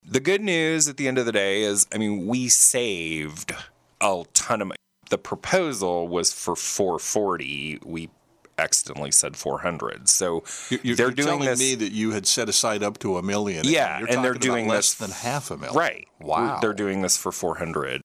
County Administrator Phillip Smith Hanes joined in on the KSAL Morning News Extra with a look at Saline County issues, including the project to raze the abandoned jail facility on North 10th Street.